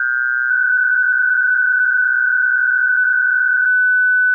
For some visual examples, this is what the filter should look like for BPSK during the preamble; the preamble is an alternating phase.
Here is what the audio sounds like with the filter: filtered.wav
filtered.wav